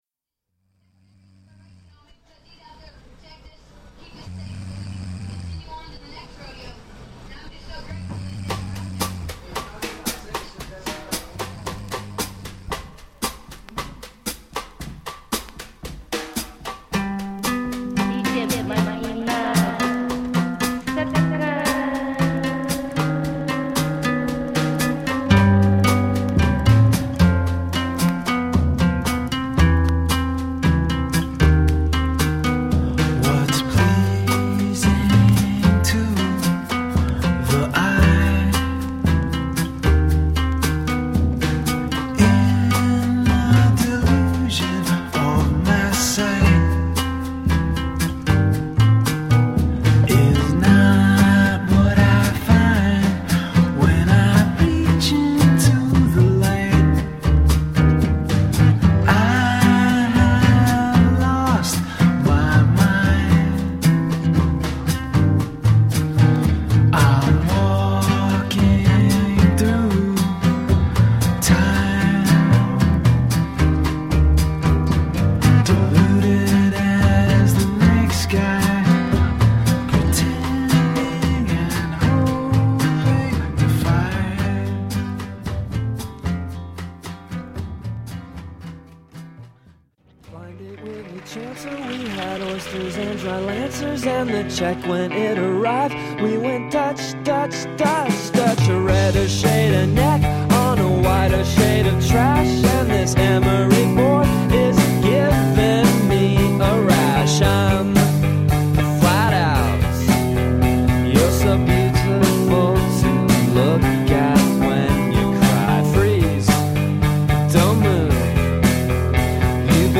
※試聴はダイジェストです。